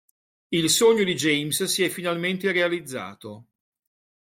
finalmente
Read more Adv Intj Frequency A2 Hyphenated as fi‧nal‧mén‧te Pronounced as (IPA) /fi.nalˈmen.te/ Etymology From finale + -mente.